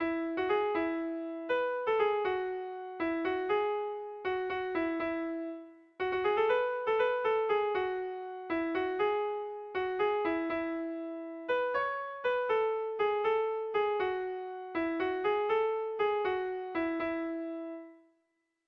Erromantzea
8A / 8A / 8 / 7A (hg) / 8A / 8A / 15A (ip)
ABDEB